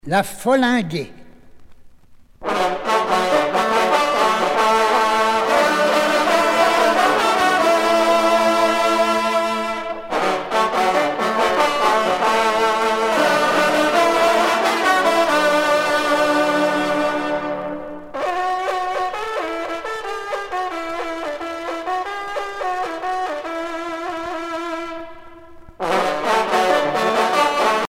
trompe - fanfare
circonstance : vénerie
Pièce musicale éditée